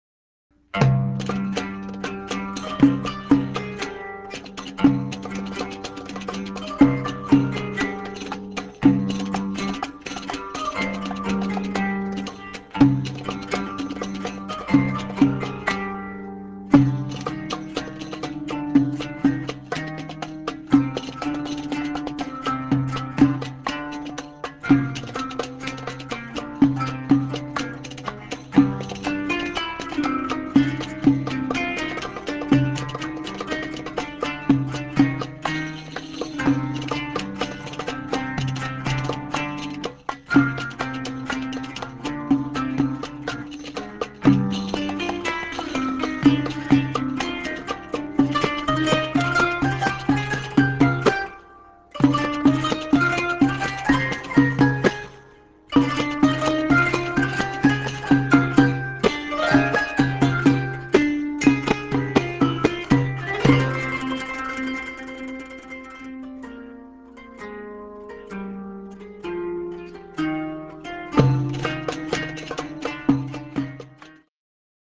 qanun (zither)
doumbec and tambourine